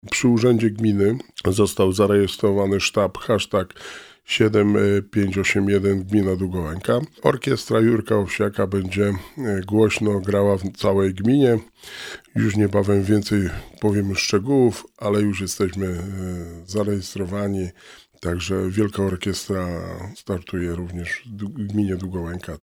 W studiu Radia Rodzina gościł Wojciech Błoński, wójt Gminy Długołęka.